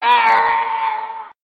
minecraft / sounds / mob / villager / death.ogg
death.ogg